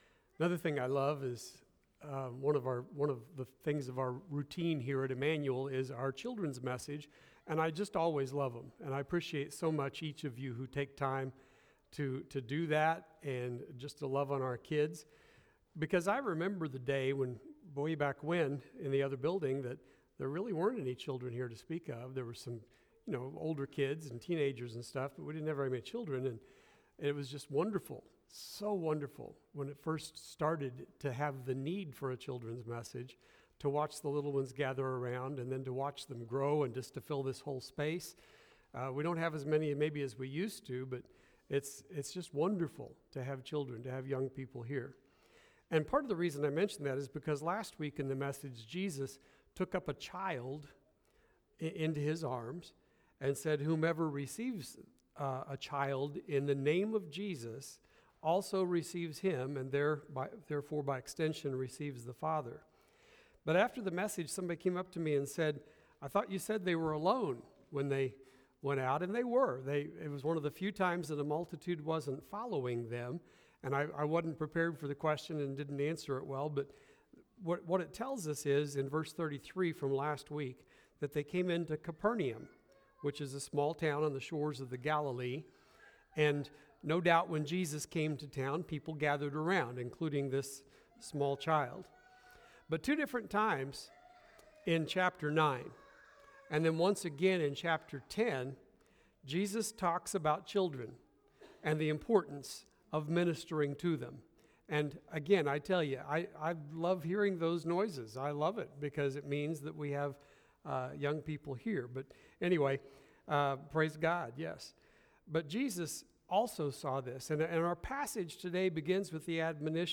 Sermon Archive – Immanuel Baptist Church